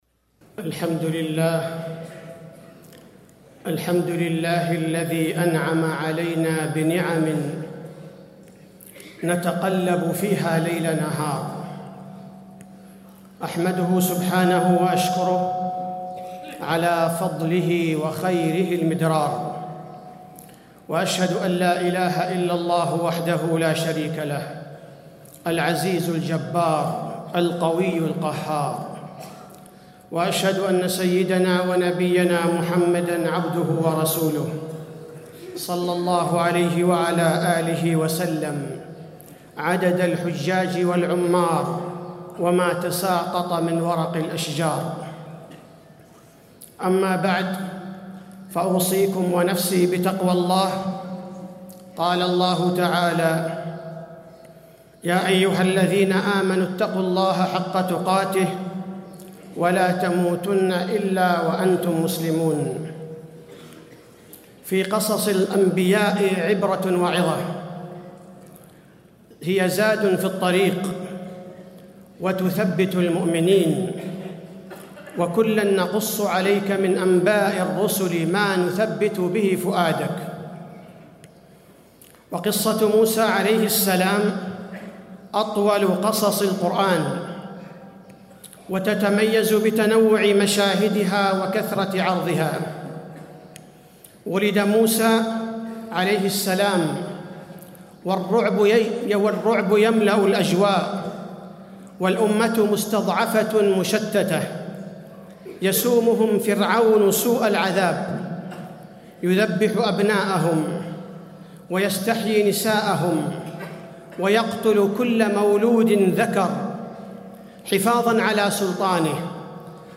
تاريخ النشر ٩ محرم ١٤٣٤ هـ المكان: المسجد النبوي الشيخ: فضيلة الشيخ عبدالباري الثبيتي فضيلة الشيخ عبدالباري الثبيتي عبرة من قصة موسى عليه السلام وفرعون